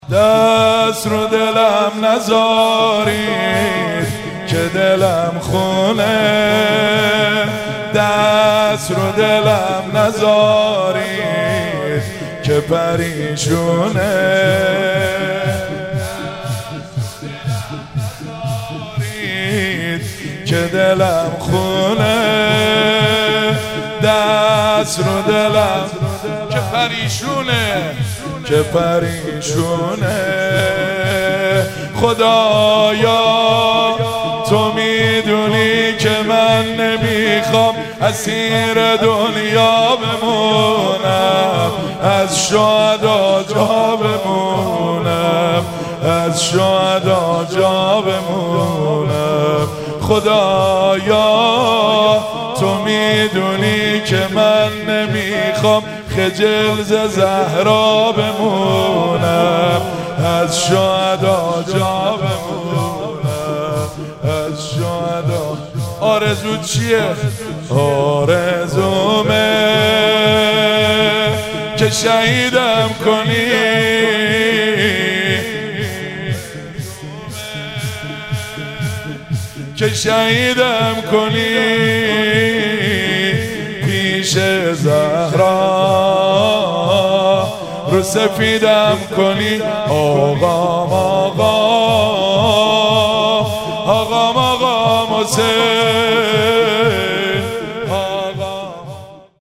مراسم مناجات خوانی شب بیست و دوم ماه رمضان 1444